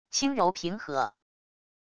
轻柔平和wav音频